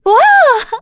Yuria-Vox_Happy4_kr_b.wav